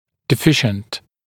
[dɪ’fɪʃnt][ди’фишнт]недостаточный, недостающий, дефективный